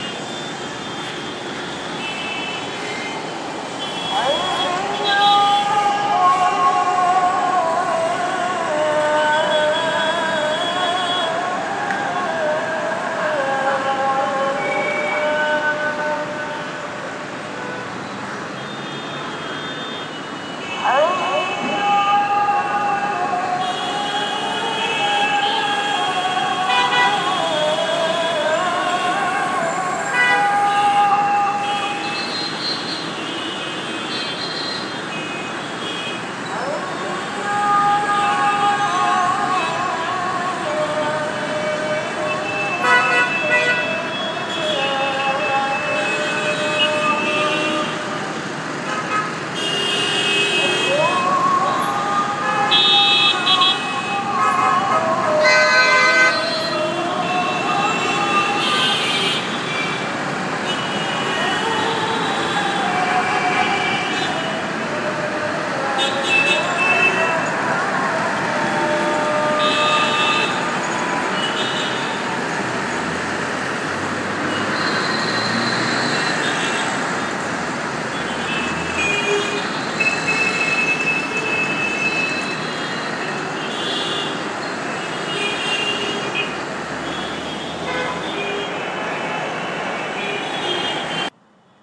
Traffic in Bandra from Skywalk